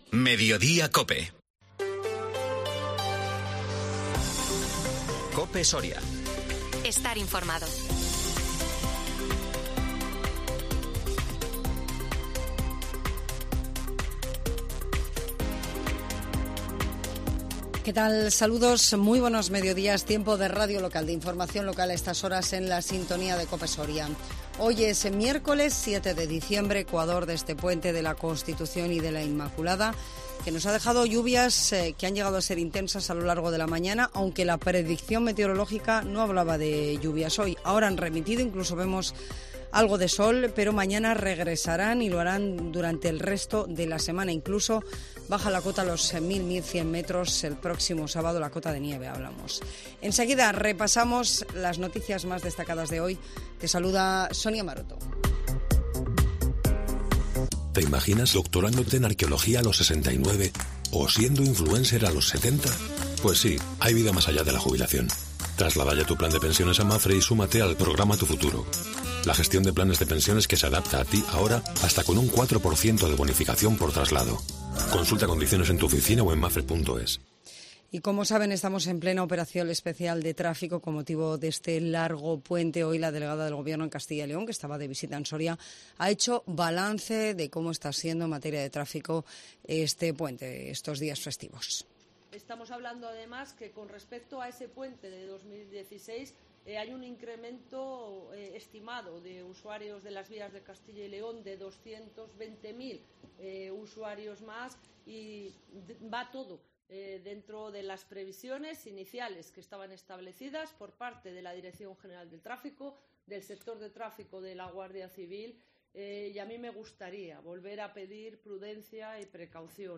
INFORMATIVO MEDIODÍA COPE SORIA 7 DICIEMBRE 2022